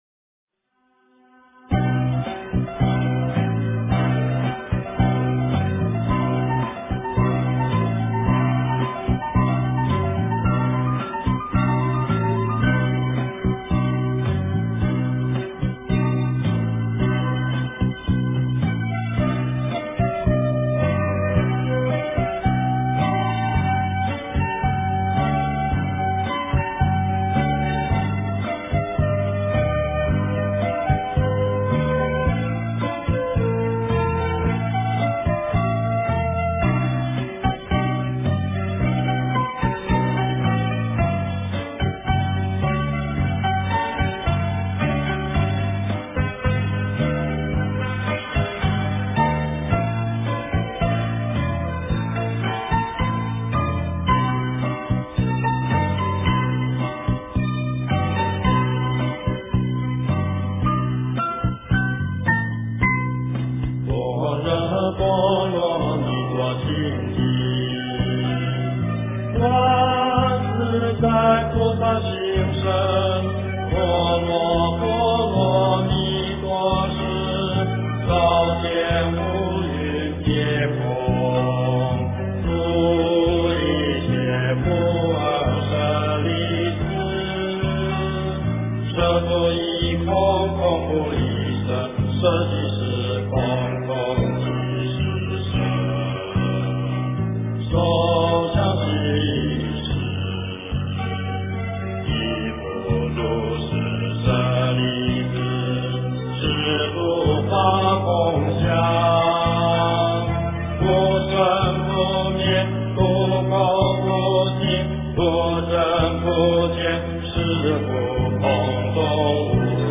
诵经
佛音 诵经 佛教音乐 返回列表 上一篇： 大悲咒 下一篇： 心经-恭诵 相关文章 观音菩萨悲赞（伴奏）--未知 观音菩萨悲赞（伴奏）--未知...